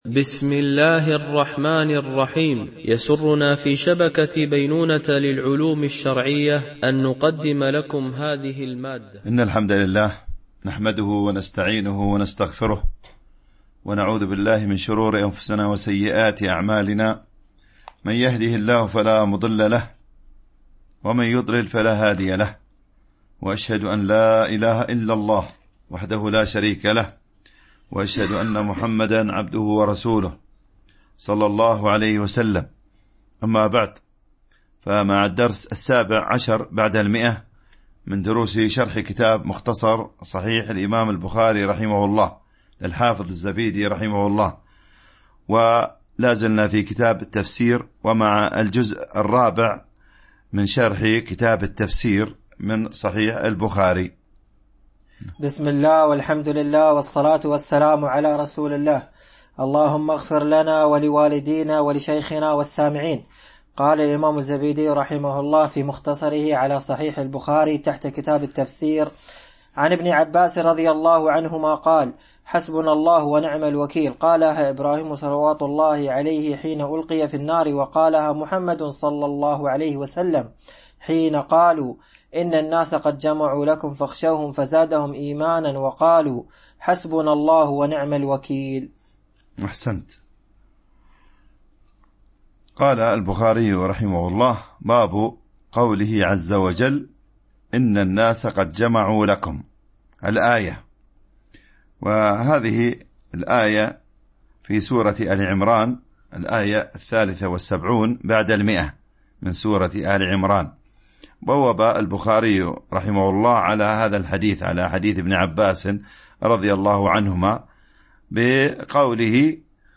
شرح مختصر صحيح البخاري ـ الدرس 117 ( كتاب التفسير ـ الجزء الرابع ـ الحديث 1726 - 1729 )
MP3 Mono 22kHz 32Kbps (CBR)